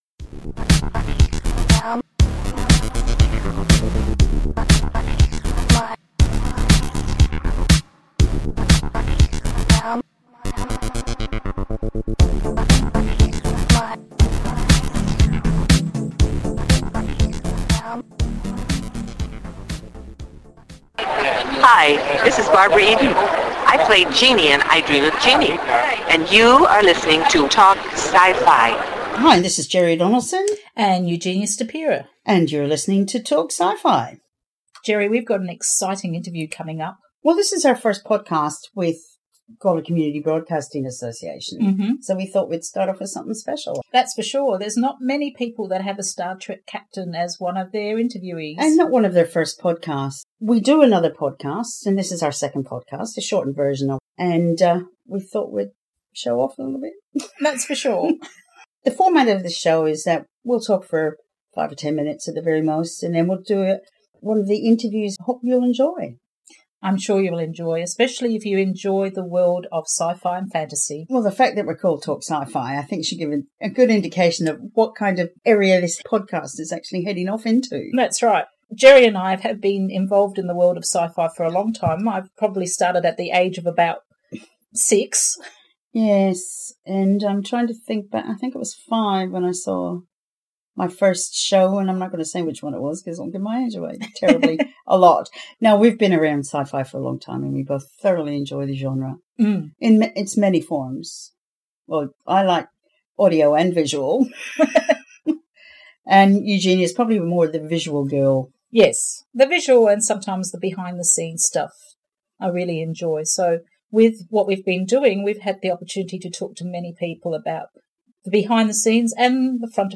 The format of the podcast is that we introduce the interview by talking about the show from which the actor etc is in.